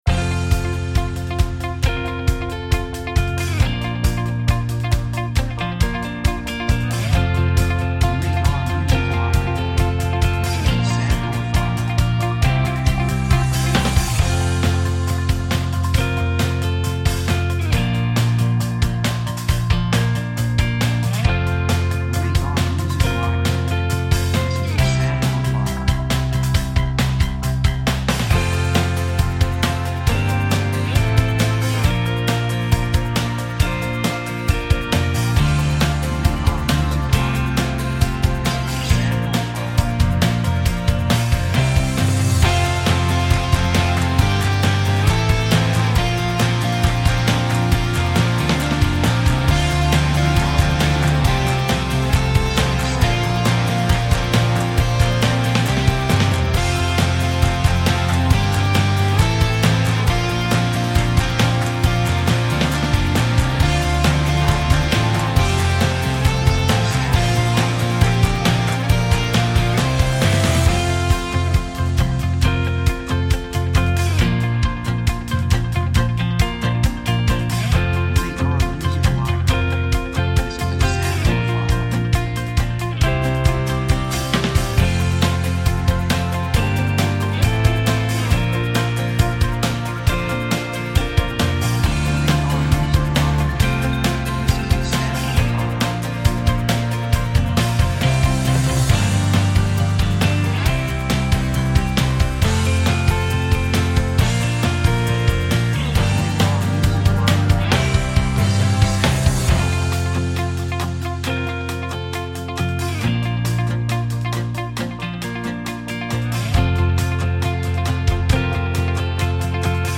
雰囲気幸せ, 高揚感, 決意, 喜び
曲調ポジティブ
楽器ピアノ, ストリングス, ボーカル, 手拍子
サブジャンルドラマ, オーケストラハイブリッド
テンポ速い
3:16 136 プロモ, ロック, スコア